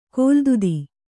♪ kōldudi